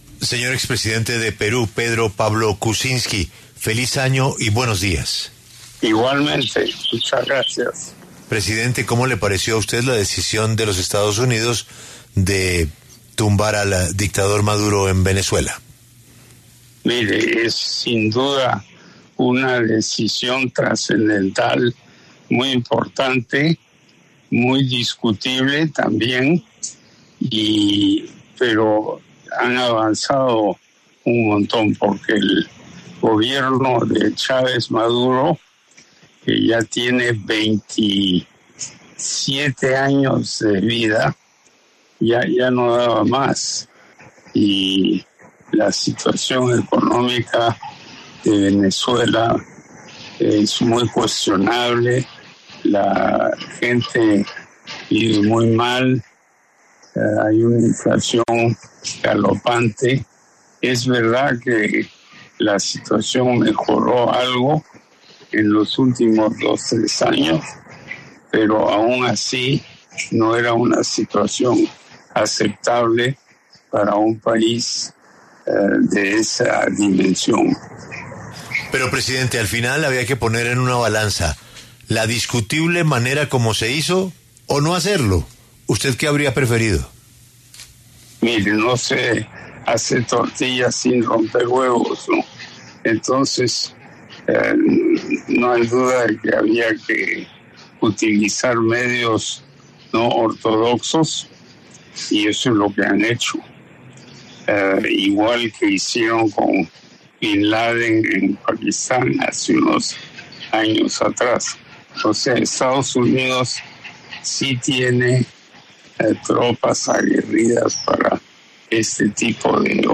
En conversación con La W, Pedro Pablo Kuczynski, expresidente de Perú, aseguró que la intervención militar de Estados Unidos en Venezuela para capturar a Nicolás Maduro era, aunque “discutida”, también “trascendental”.